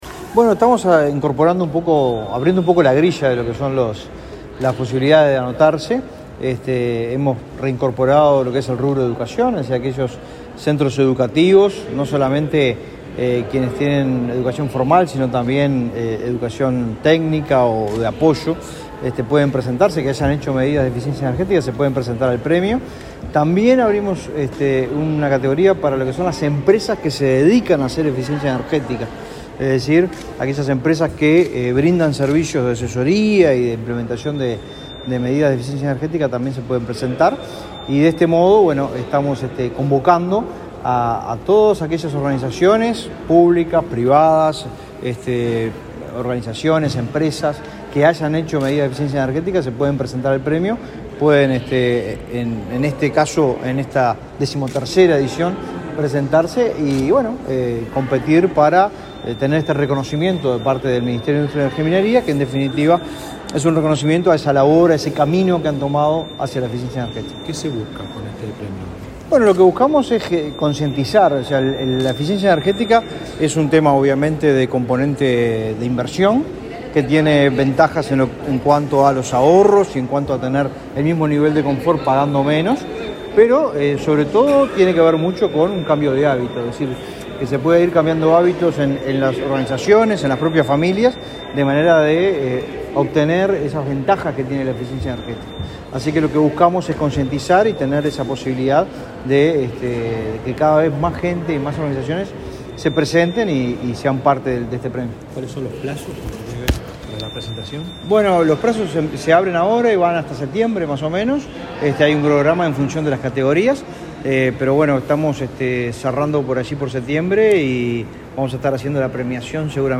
Declaraciones a la prensa del director nacional de Energía, Fitzgerald Cantero